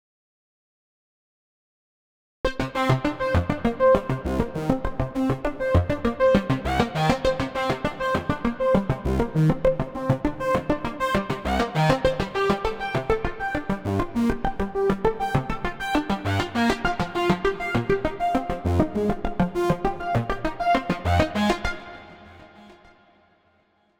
Sequenz aus einem ARP 2600
Arp2600PitchSeq.mp3